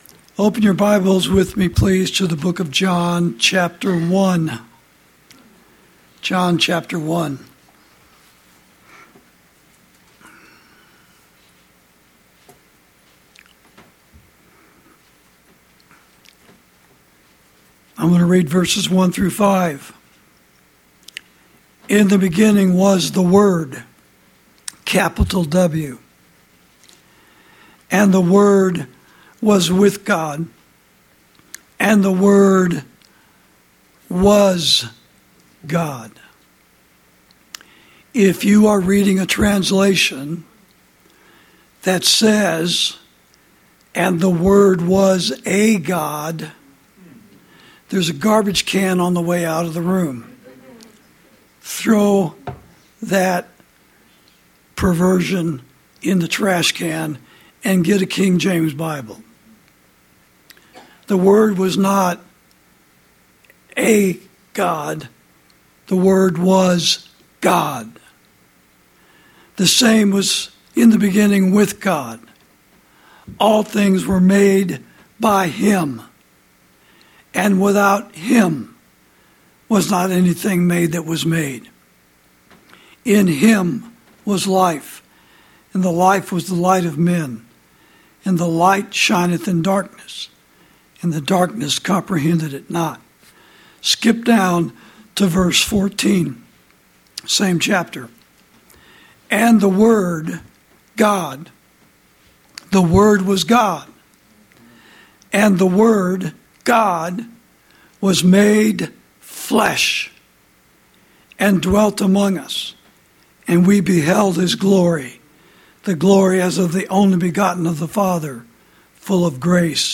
Sermons > At His Birth